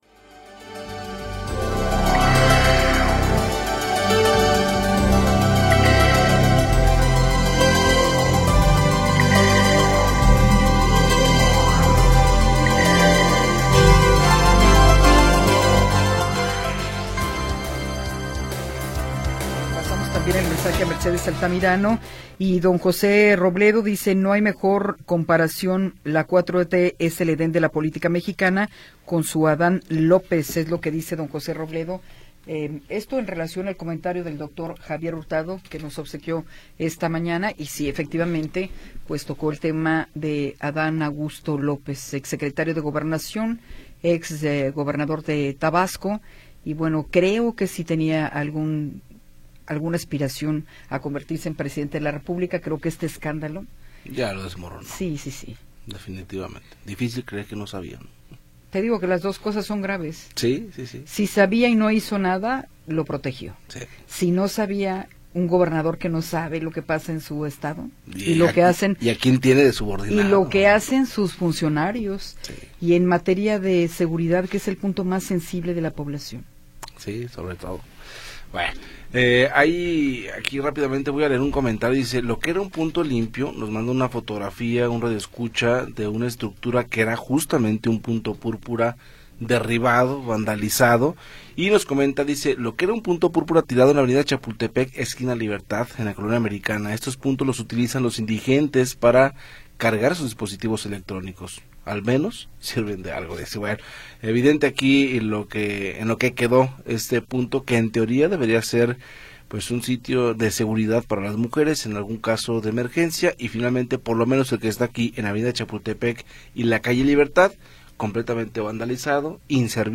Información oportuna y entrevistas de interés